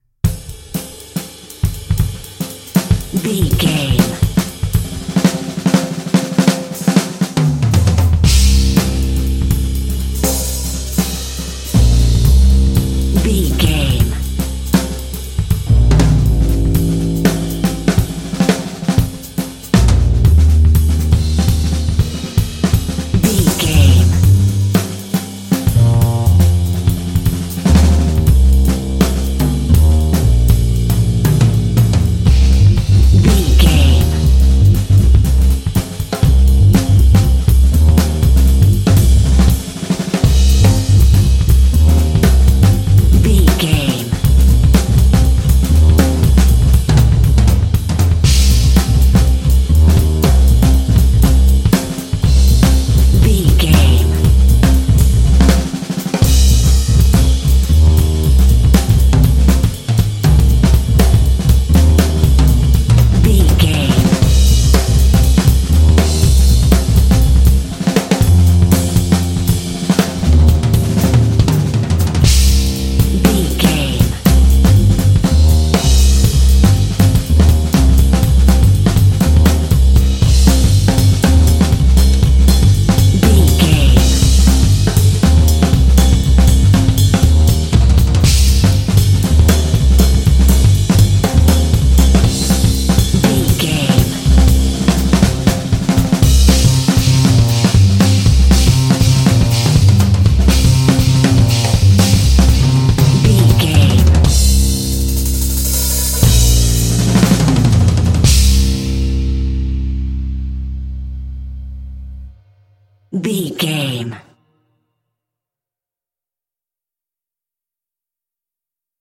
Aeolian/Minor
Fast
groovy
double bass
jazz
jazz drums